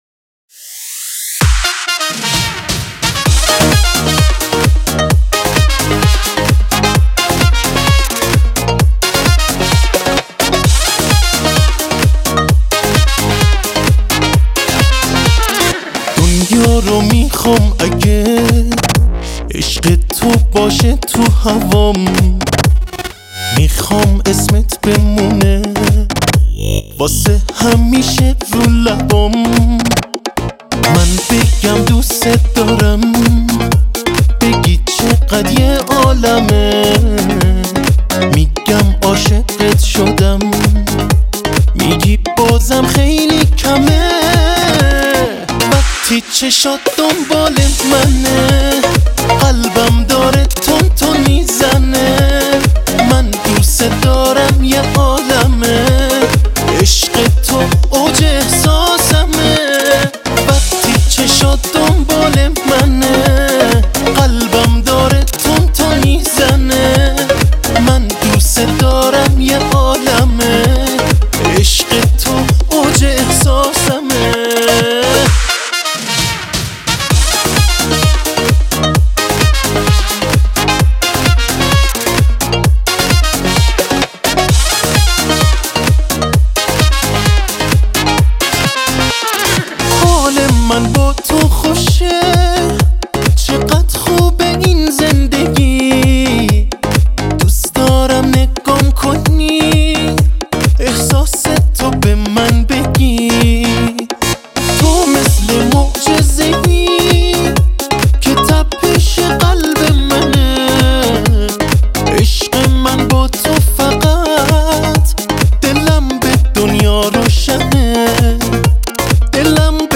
ترومپت